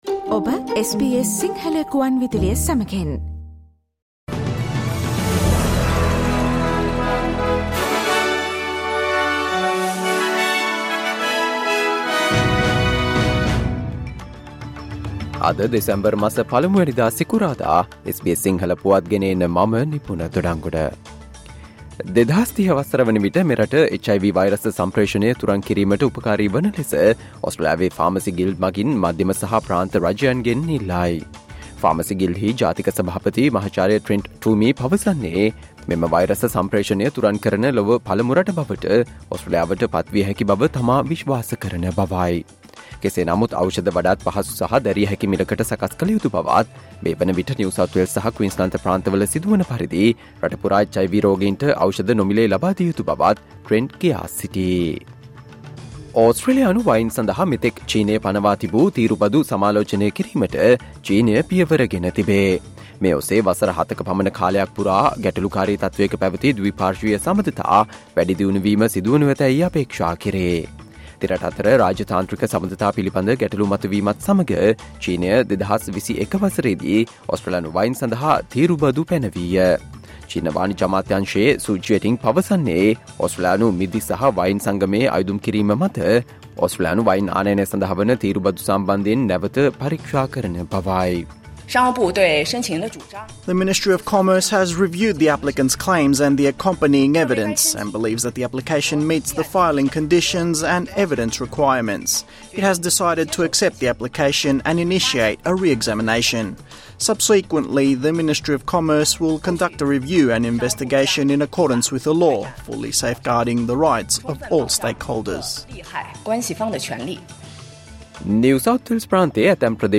Australia news in Sinhala, foreign and sports news in brief - listen, Friday 01 December 2023 SBS Sinhala Radio News Flash